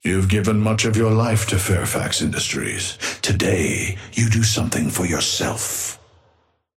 Amber Hand voice line - You've given much of your life to Fairfax Industries.
Patron_male_ally_forge_start_02.mp3